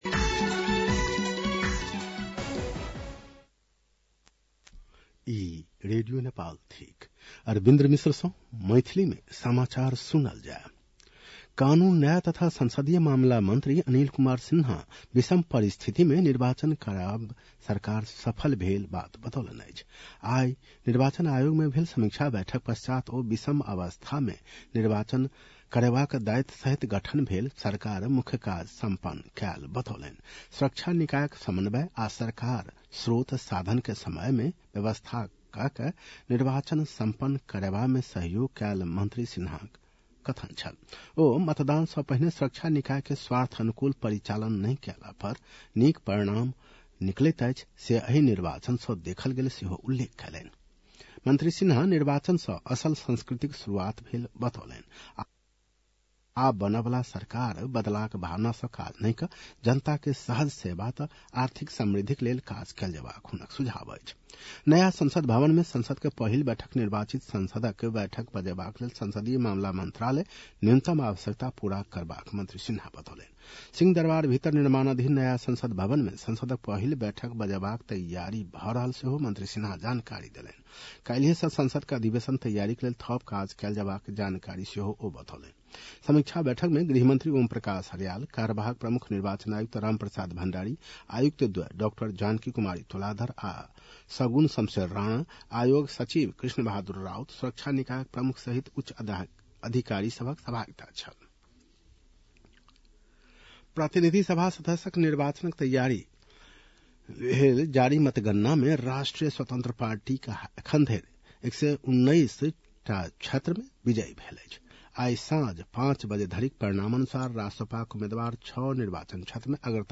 मैथिली भाषामा समाचार : २४ फागुन , २०८२
6.-pm-maithali-news-.mp3